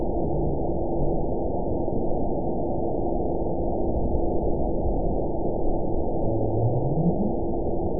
event 917228 date 03/24/23 time 22:28:25 GMT (2 years, 1 month ago) score 9.52 location TSS-AB04 detected by nrw target species NRW annotations +NRW Spectrogram: Frequency (kHz) vs. Time (s) audio not available .wav